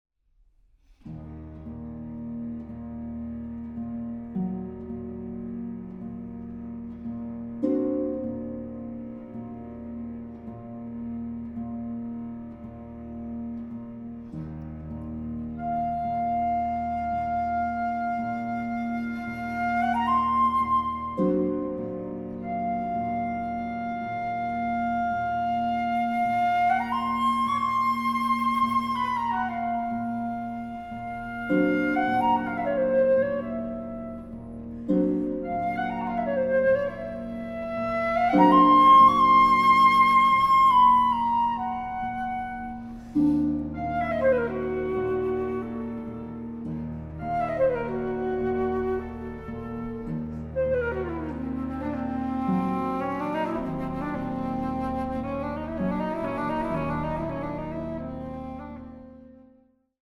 arranged for flute, cello, and harp